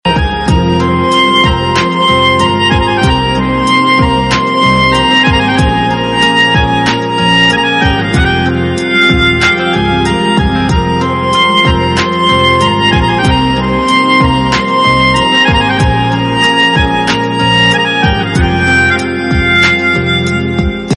Its instrumental form offers a unique charm.